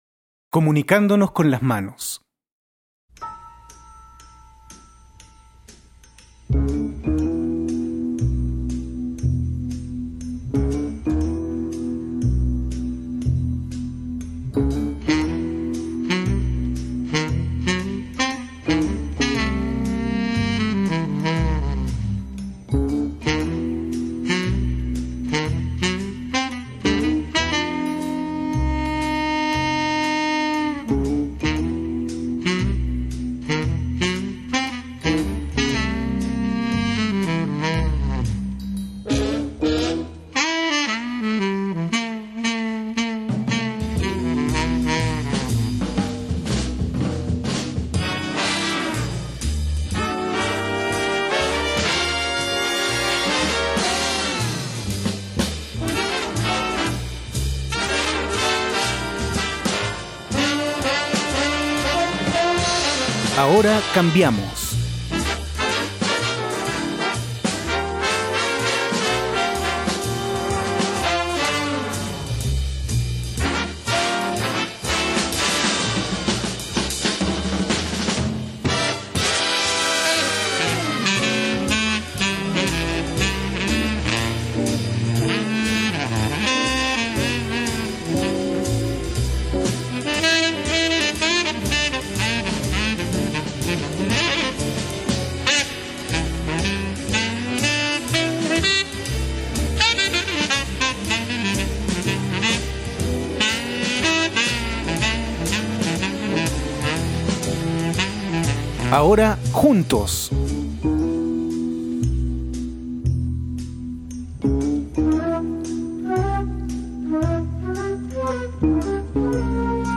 con algunas instrucciones de voz para realizar la actividad.